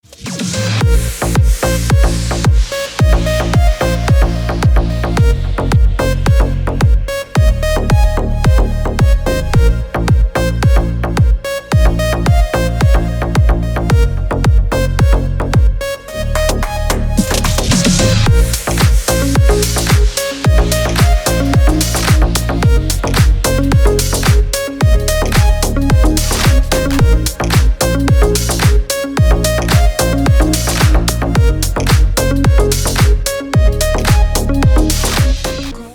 Deep House 2024 под рингтон